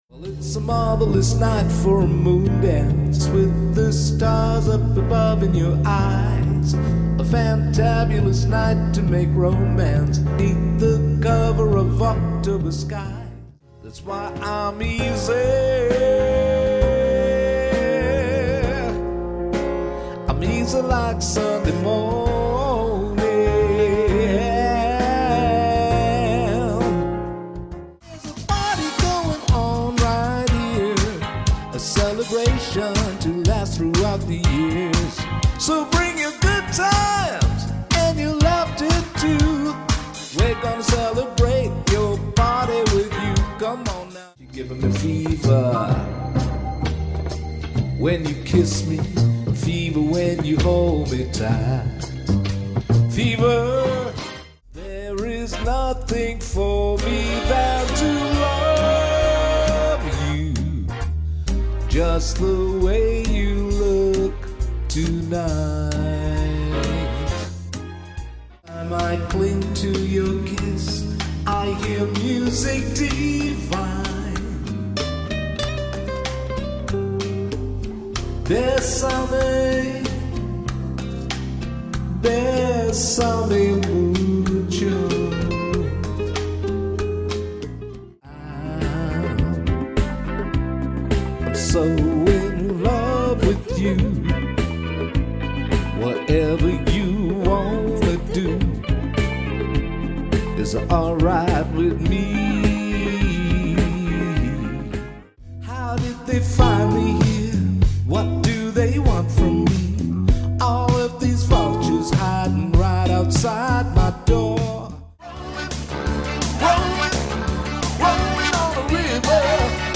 Jazz, Blues, R&B